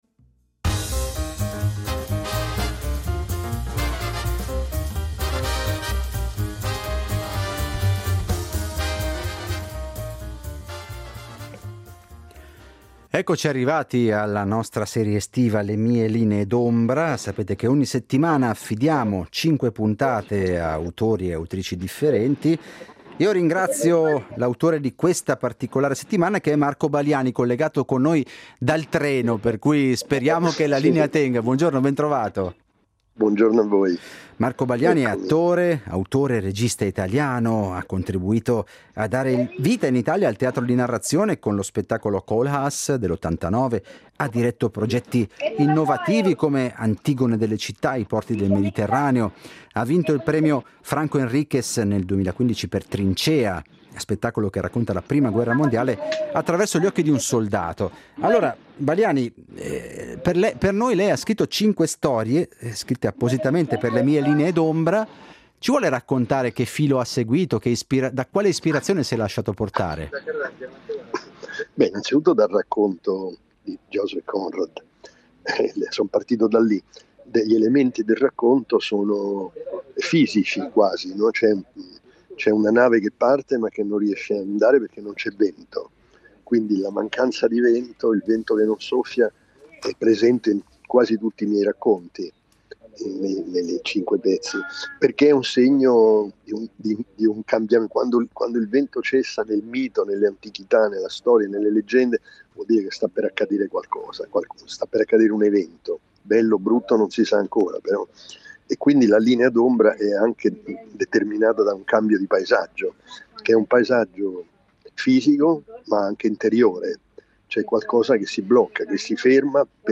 Intervista all’attore, autore e regista italiano